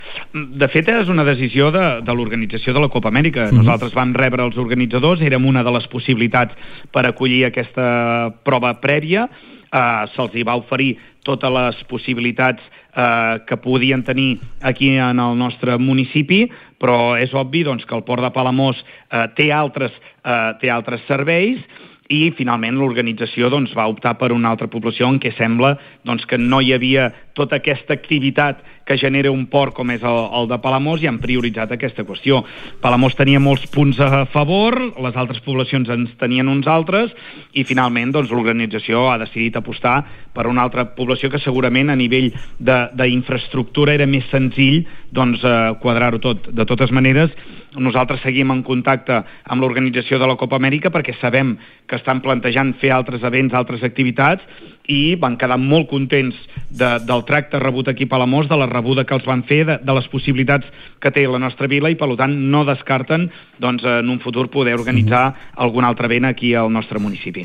Ho explicava també al Supermatí l’alcalde del consistori palamosí, Lluís Puig.